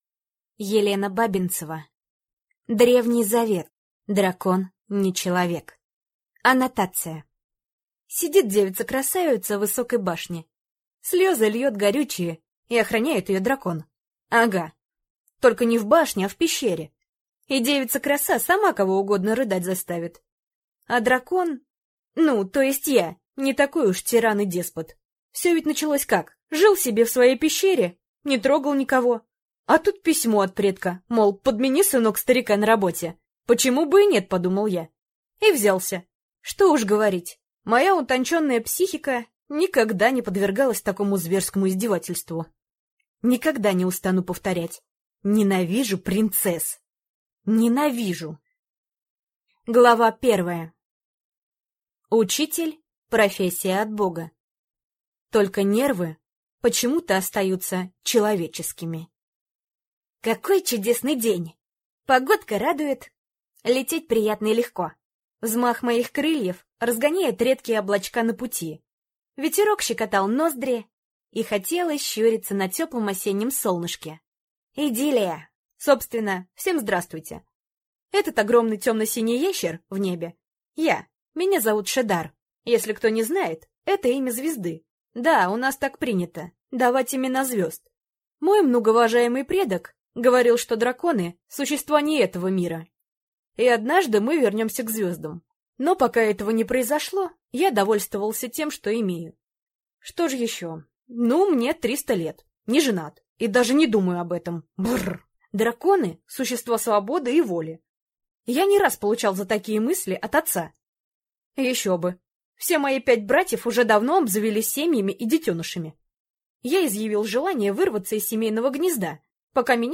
Аудиокнига Древний завет. Дракон не человек | Библиотека аудиокниг